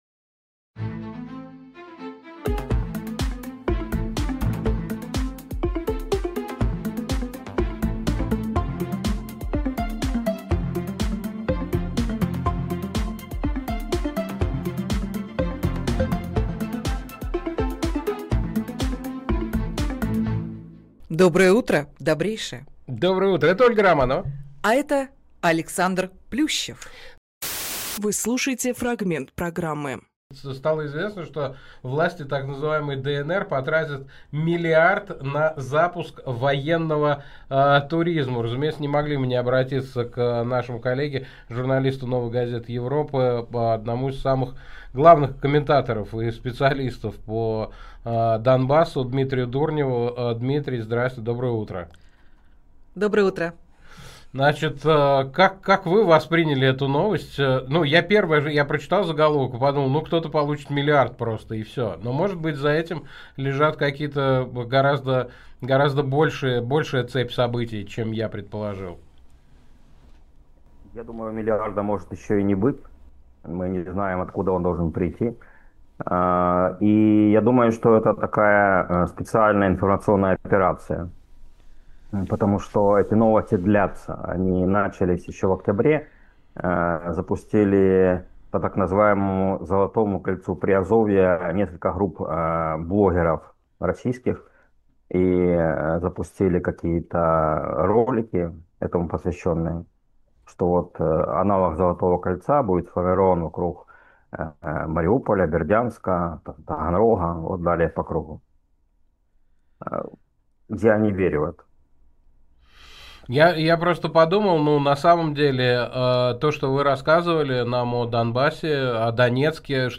Фрагмент эфира от 17.11.25